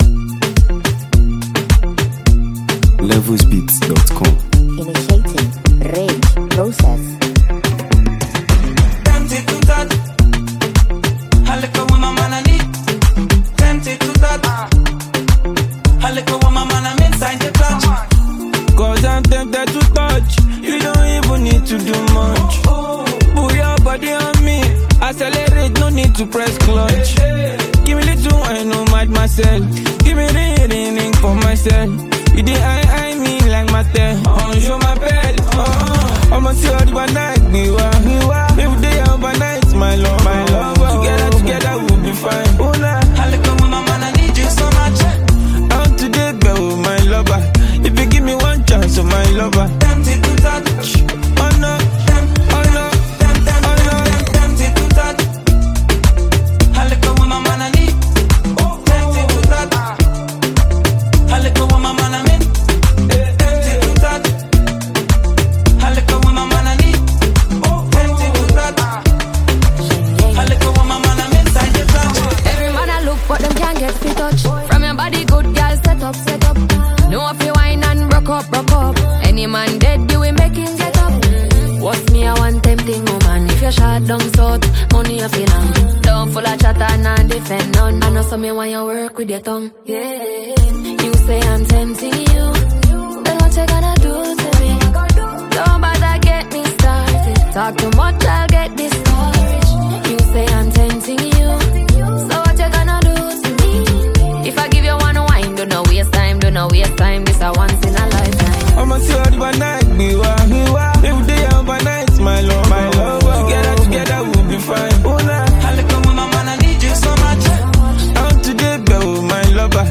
high-energy track